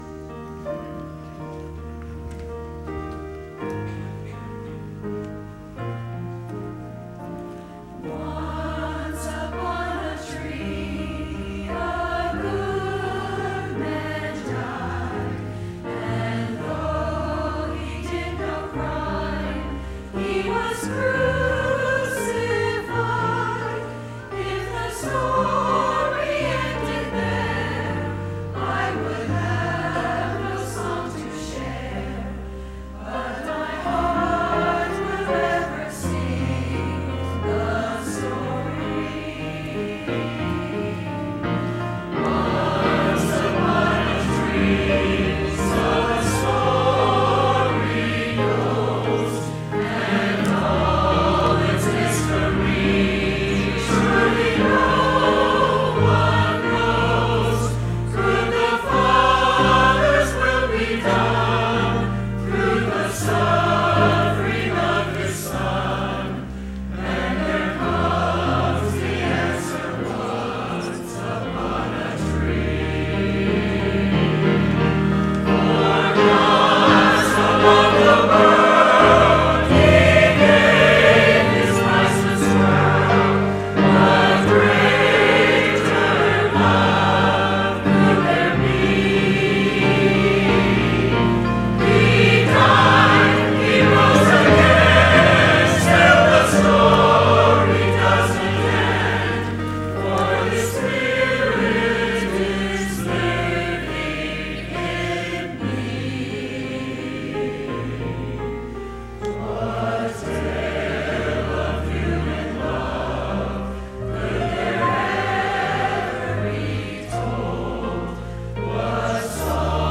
“Once Upon A Tree” ~ Choir